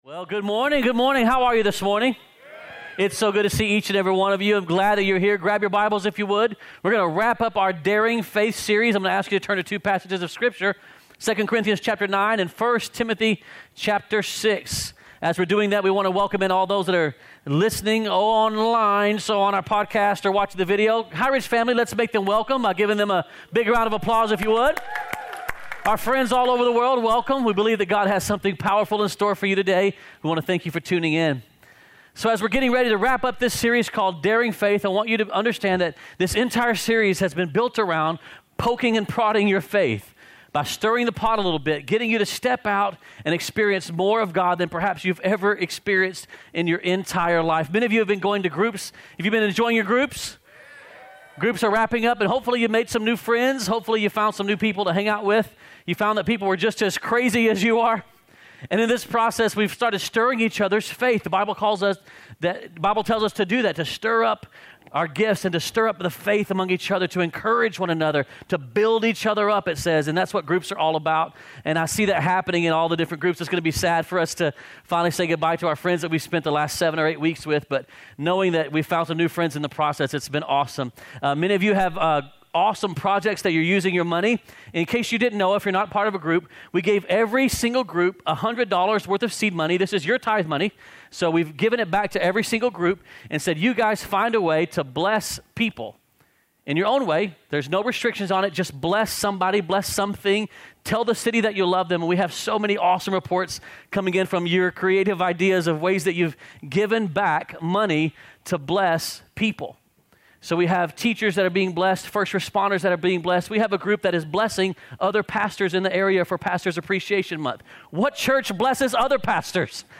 2018 Sermon